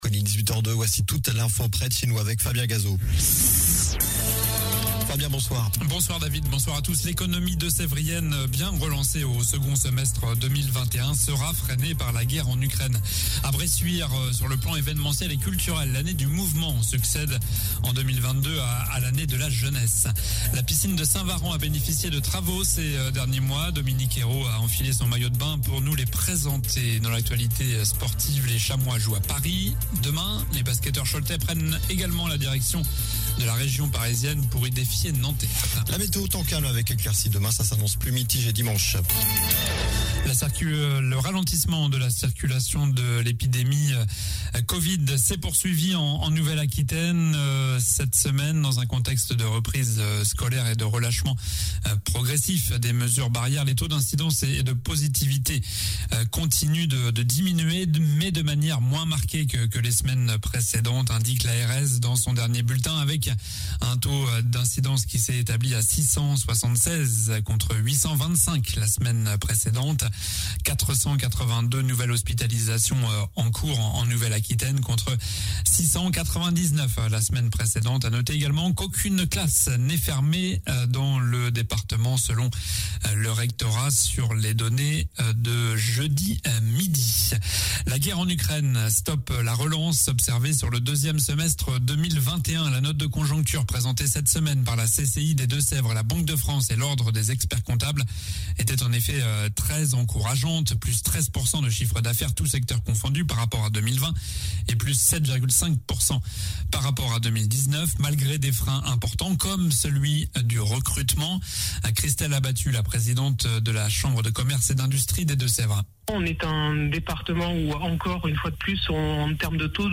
Journal du vendredi 11 mars (soir)